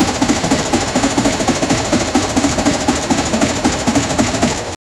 Steam Engine Chug‑Chug — warm “chug‑chug‑choo” rhythm, instantly evoking classic locomotives
steam-engine-chugchug-war-jjpb2dgp.wav